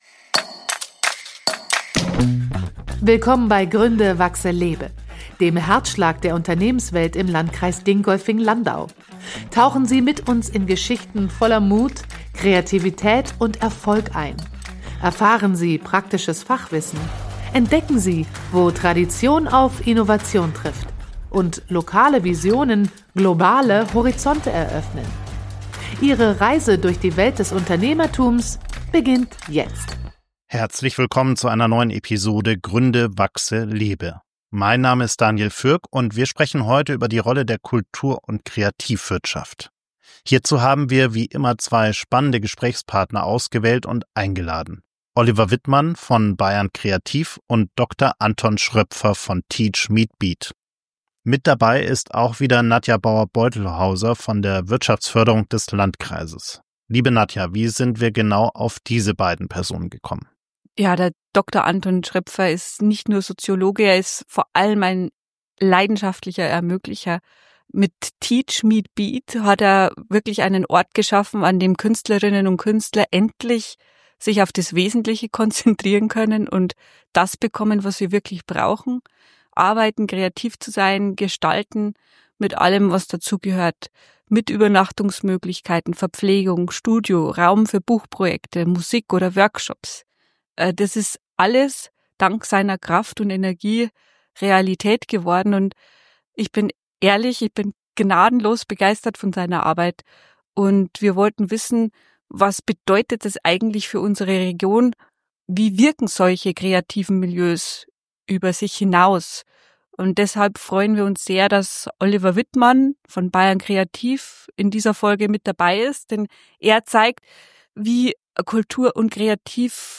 Ein Gespräch über Förderstrukturen, kreative Räume, fehlende Netzwerke – und die Kraft, die in Musik, Verantwortung und Austausch steckt.